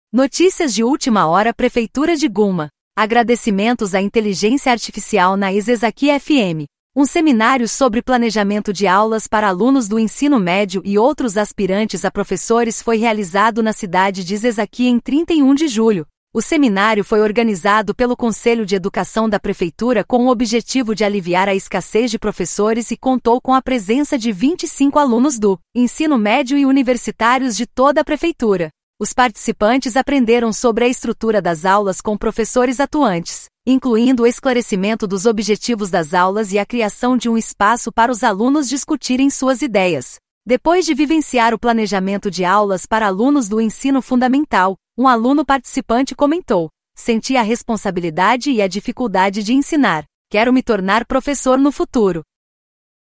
Notícias de última hora "Prefeitura de Gunma".Agradecimentos à IA na "Isesaki FM".Um seminário sobre planejamento de aulas para alunos do ensino médio e outros aspirantes a professores foi realizado …
Audio Channels: 1 (mono)